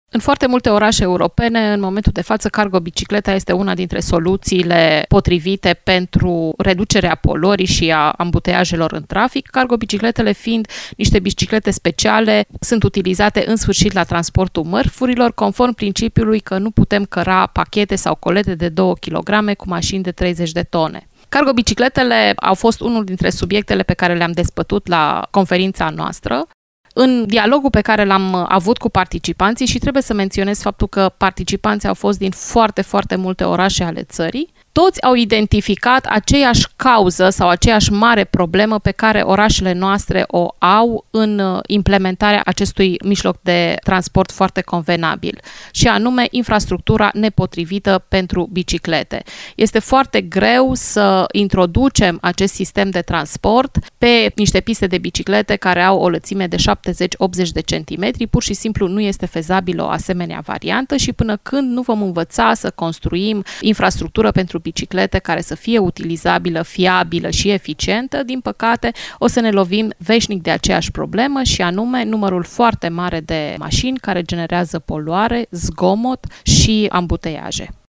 Peste 100 de specialiști din domeniul mobilității urbane, reprezentanți ai autorităților publice și ai organizațiilor din acest domeniu au participat la cele două zile de conferință on-line.